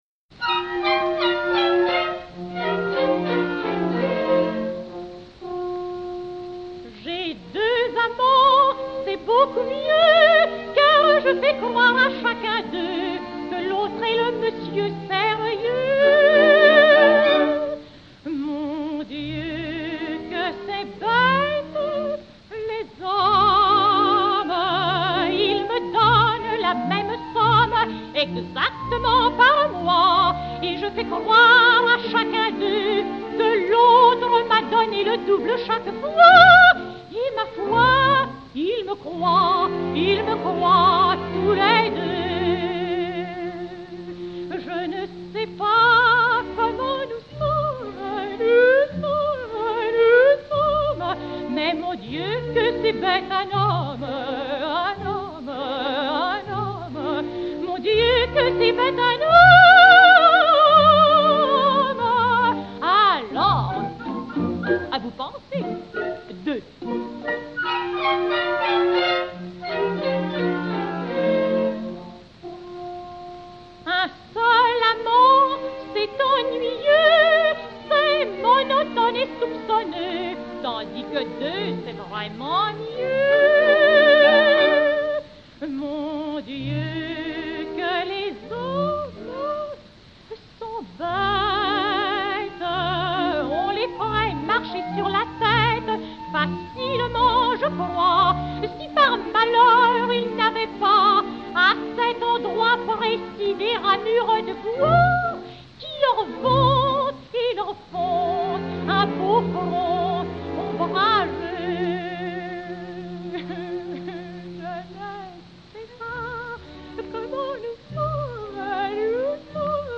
enr. à Londres le 12 juillet 1929